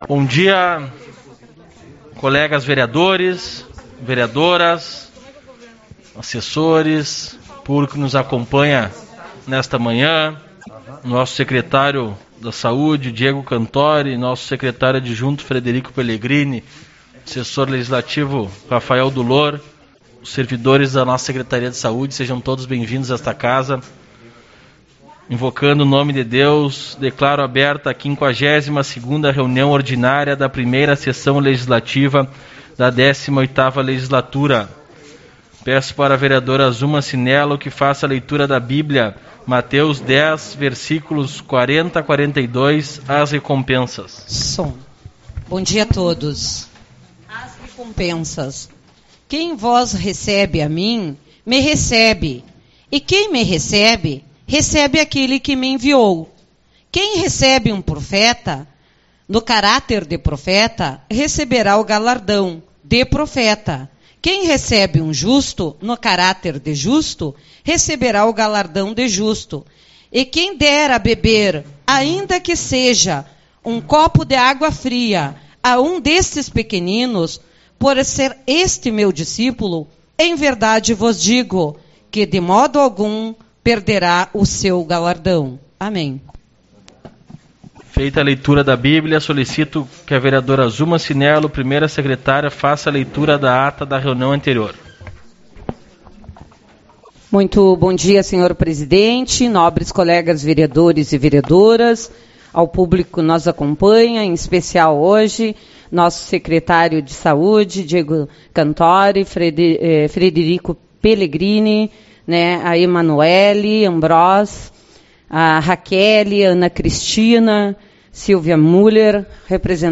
17/08 - Reunião Ordinária